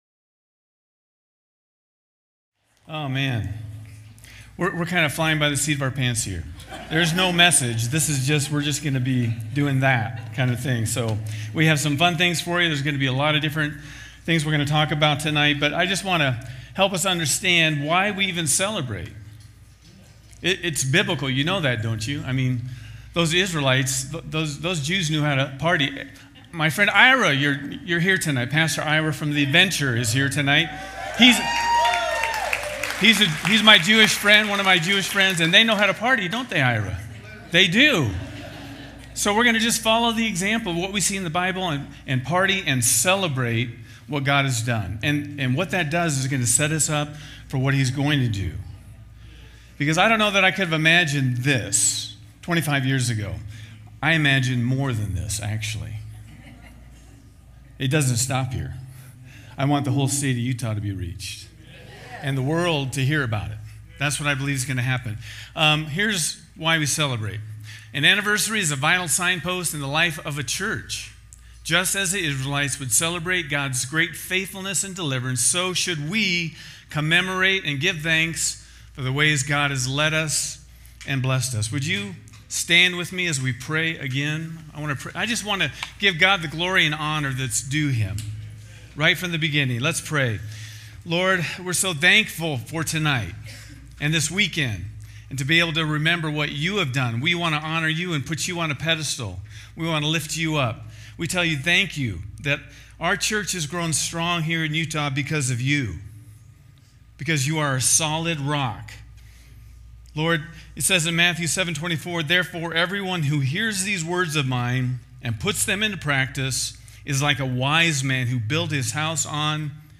Anniversary Celebration Weekend